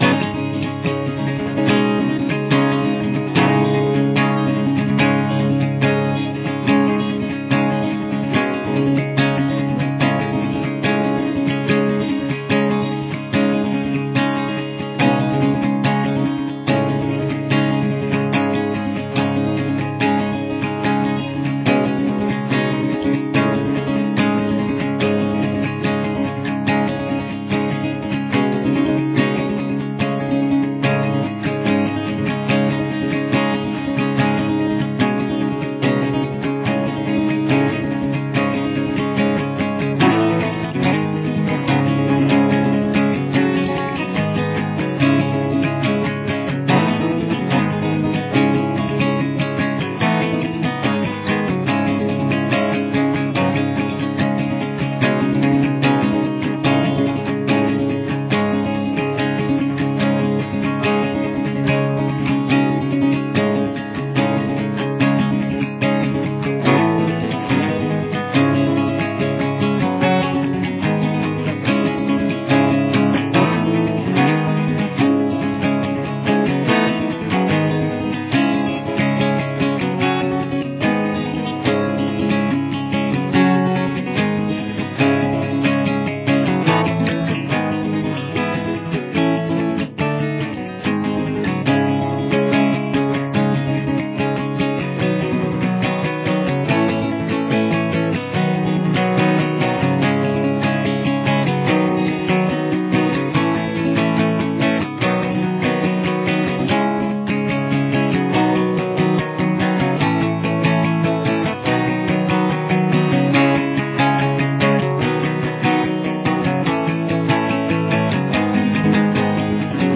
CAPO – Fourth Fret
This is the full version, just playing chords.